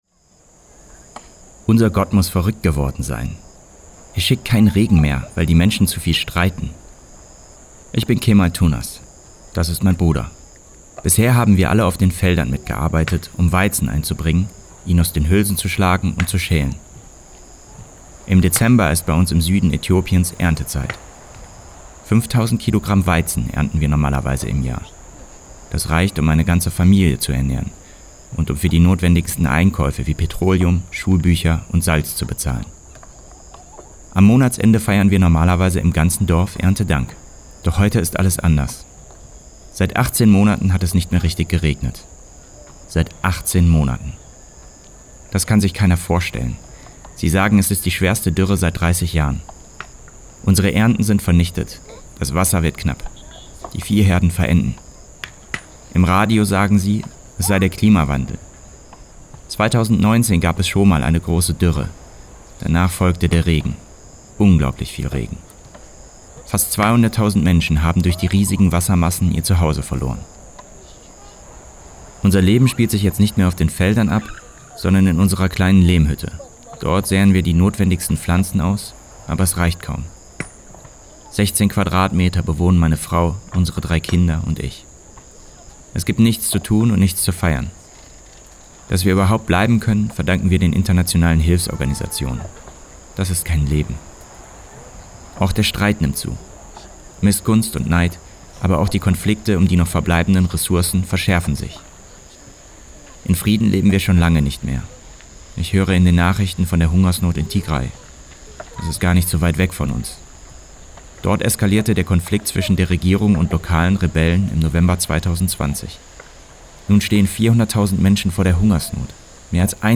Ein Feldarbeiter erzählt: